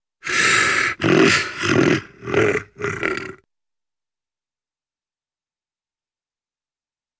Звуки бульдога
Смешной звук бульдога